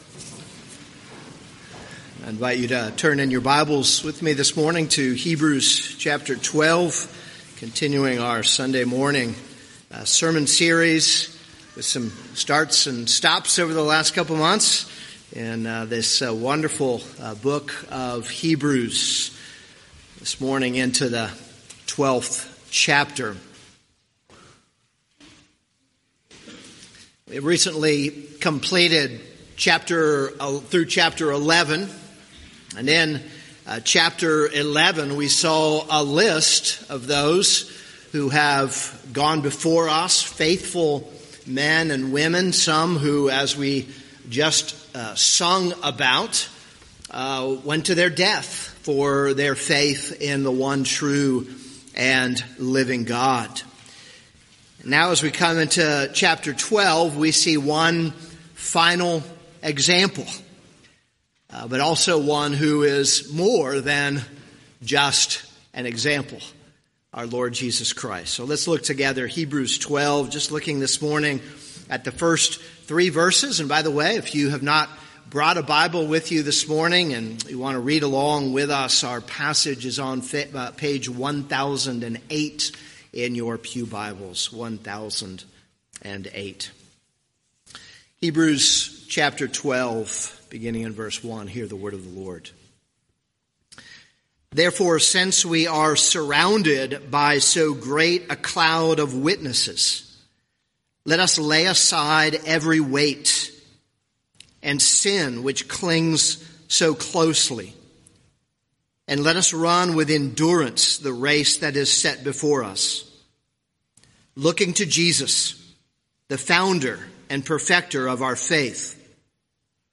This is a sermon on Hebrews 12:1-3.